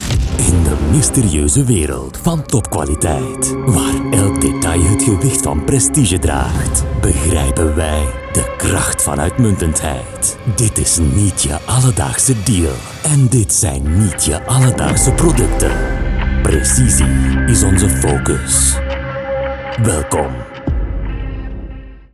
Adult (30-50)
Movie Trailer Voice Overs
0703FLEMISH_Trailer.mp3